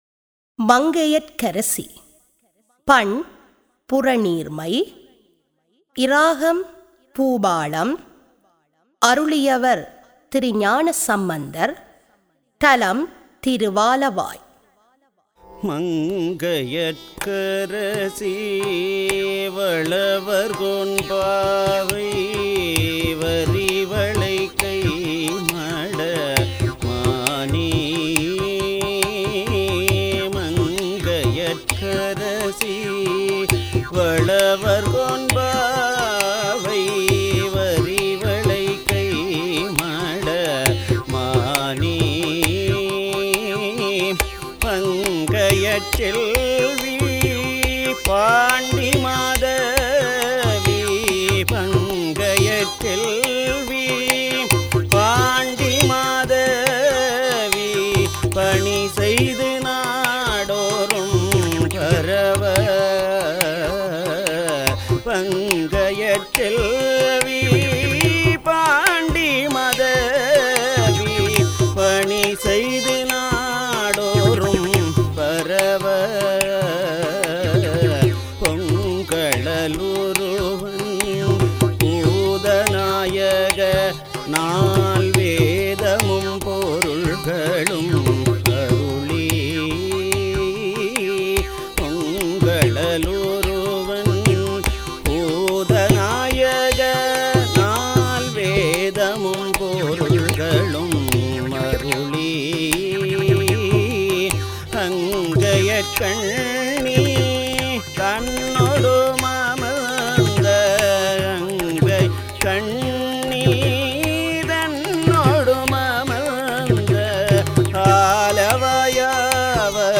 தரம் 9 இல் கல்வி பயிலும் சைவநெறிப் பாடத்தை கற்கும் மணவர்களின் நன்மை கருதி அவர்கள் தேவாரங்களை இலகுவாக மனனம் செய்யும் நோக்கில் இசைவடிவக்கம் செய்யப்பட்ட தேவாரப்பாடல்கள் இங்கே பதிவிடபட்டுள்ளன.